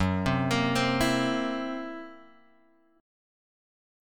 F#7b5 chord {2 3 x 3 1 0} chord